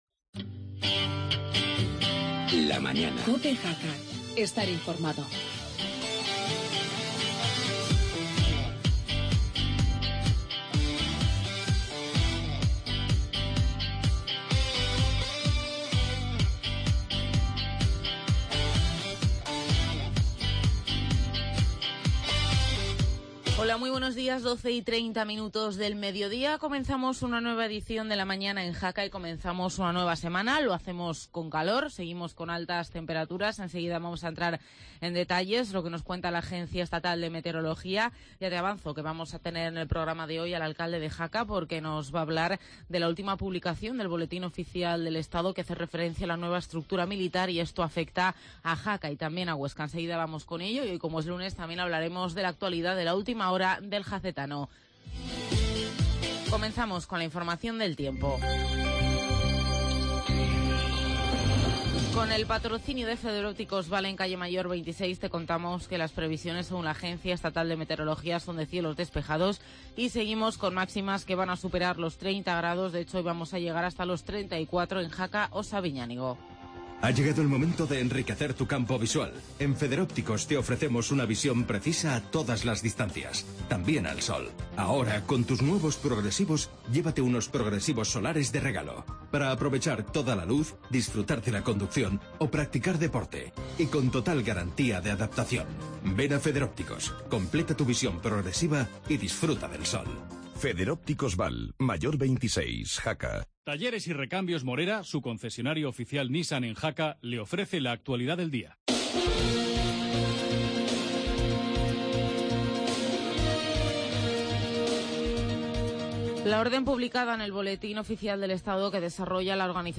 Hoy con el alcalde de Jaca, Juan Manuel Ramón Ípas hablando de la salida de la Jefatrura de Tropas de Montaña de Jaca.